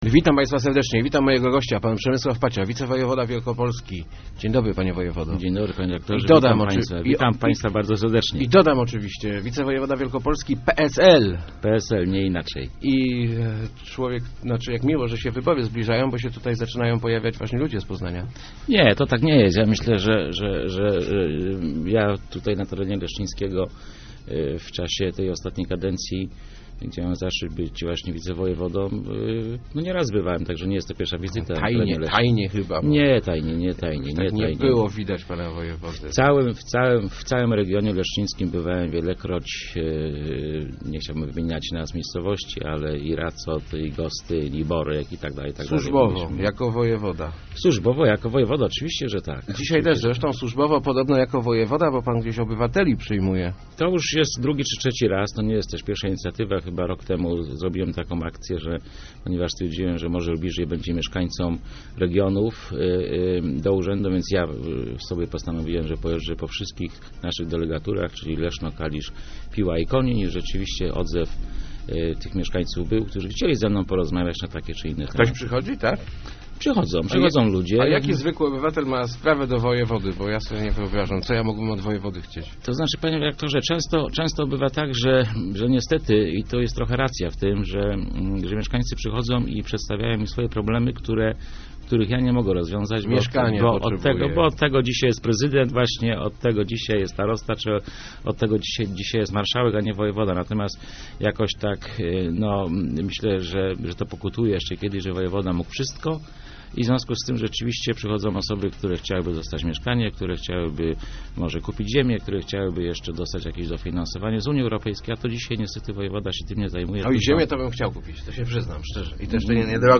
Chciałbym, żeby wróciło województwo leszczyńskie - mówił w Rozmowach Elki wicewojewoda wielkopolski Przemysław Pacia. Jest on kandydatem PSL w wyborach do Sejmu w okręgu kalisko-leszczyńskim.